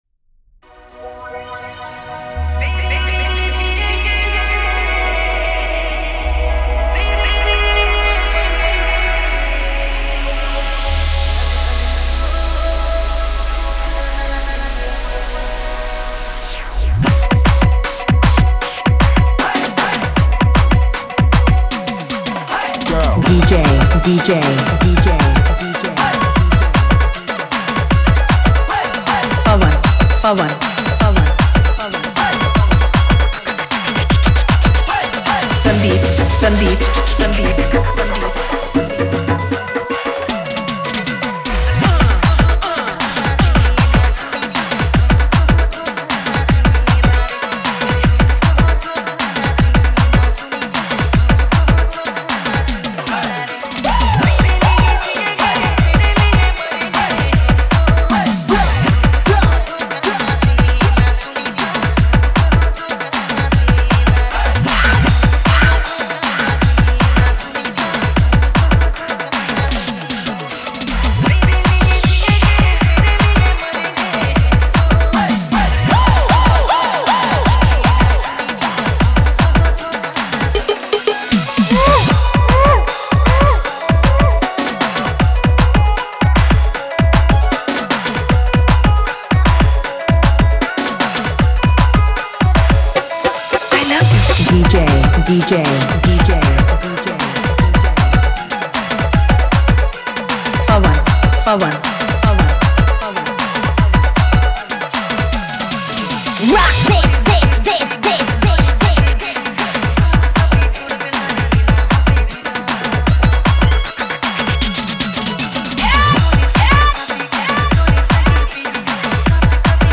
Experience the soulful Nagpuri beats